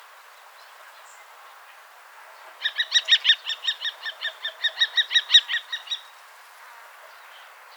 FALCO TINNUNCULUS - KESTREL - GHEPPIO
- POSITION: Poderone near Magliano in Toscana, LAT.N 42°36'/LONG.E 11°17'- ALTITUDE: +130 m. - VOCALIZATION TYPE: contact calls.
- COMMENT: Only one bird is calling. It calls several times and only one call series is recorded.